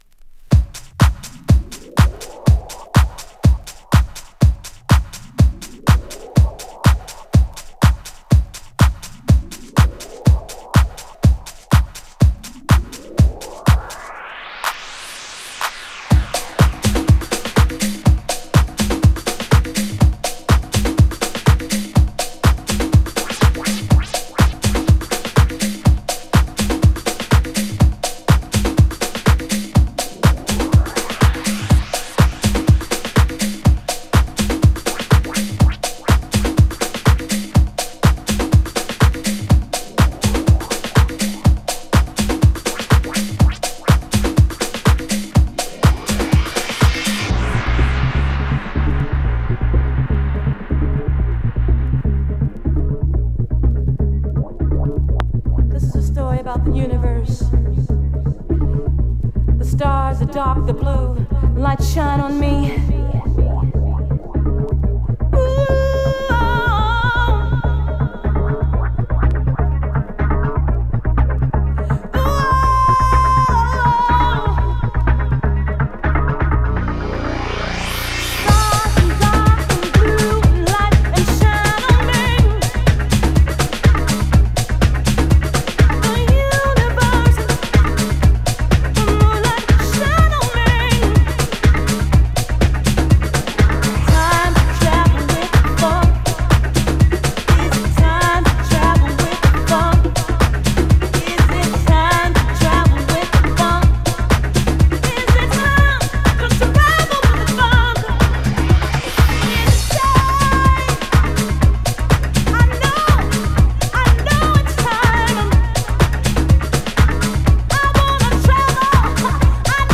超定番パーカッショントラック